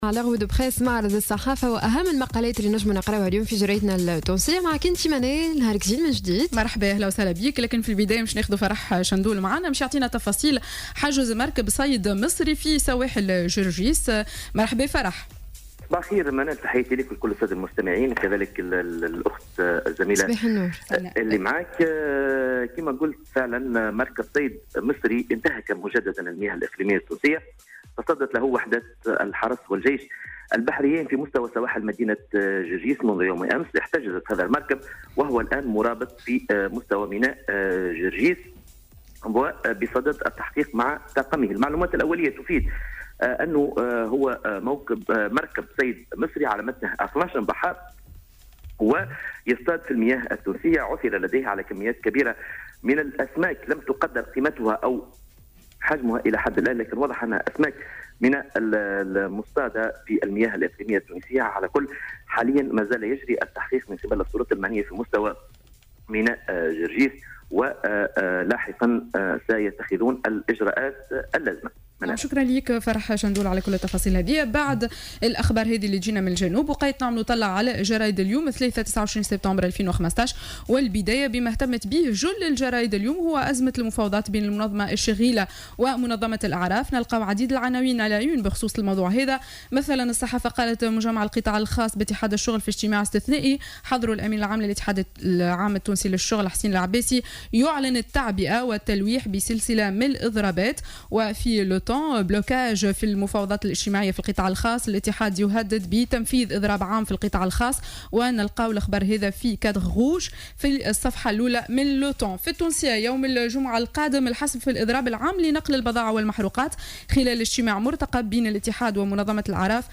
Revue de presse du mardi 29 septembre 2015